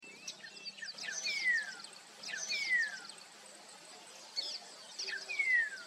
Bluish-grey Saltator (Saltator coerulescens)
Life Stage: Adult
Location or protected area: Miramar de Ansenuza
Condition: Wild
Certainty: Recorded vocal